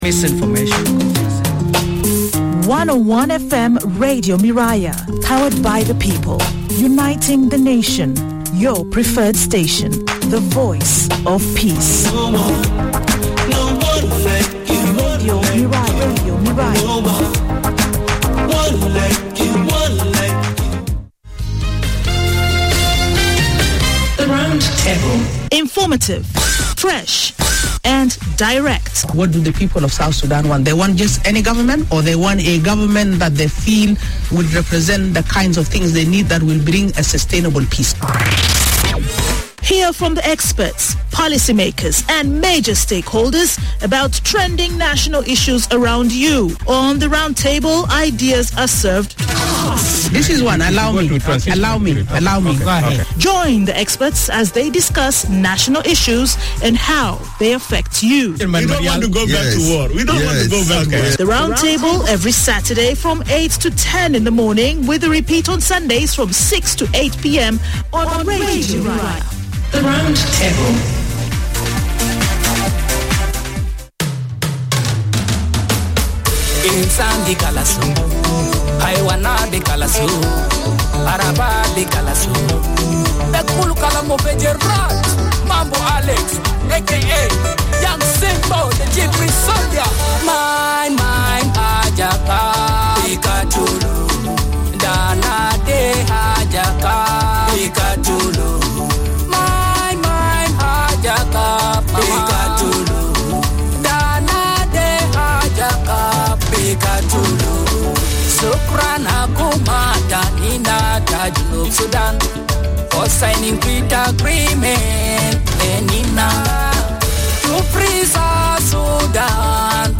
Round Table Part 01 Discussion on the upcoming launch of a 36 member technical committee to conduct nationwide consultations on the design of the Commission on Truth, Reconciliation and Healing in all the states and three administrative areas of South Sudan.